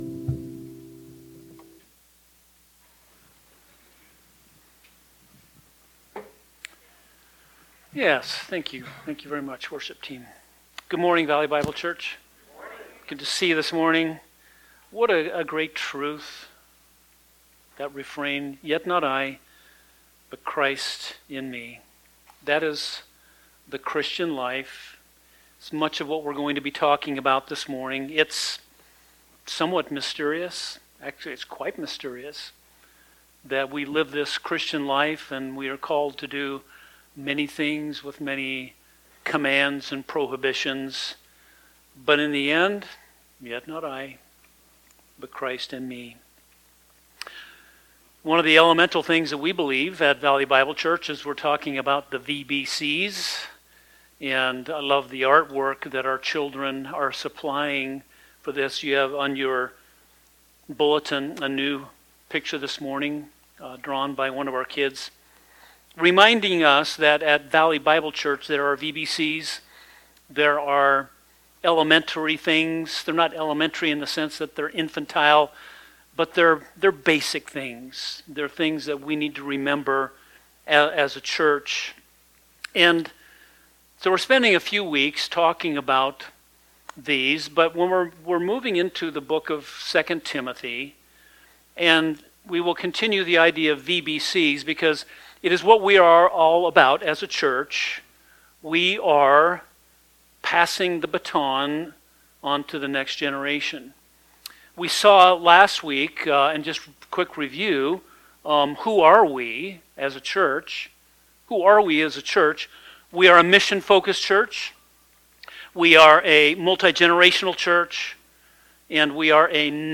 The congregation is encouraged to continue in the things they have learned and to be convinced of the wisdom that leads to salvation through faith in Christ.